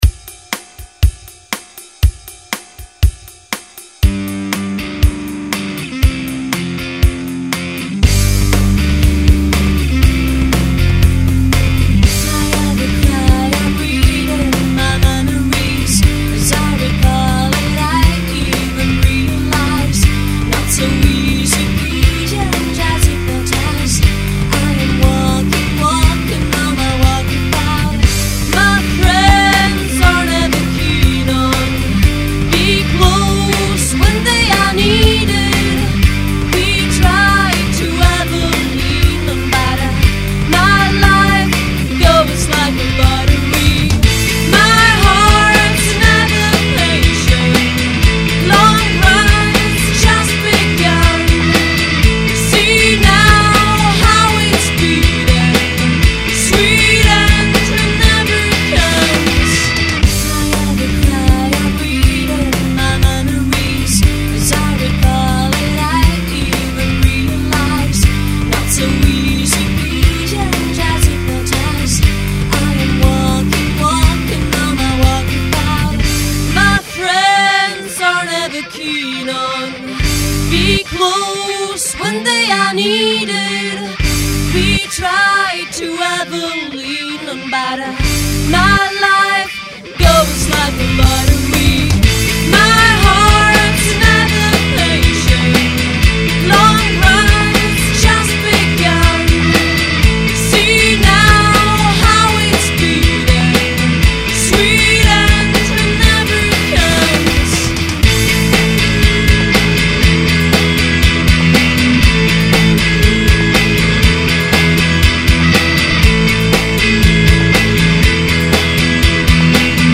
kytara